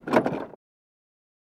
Cessna Pedal Up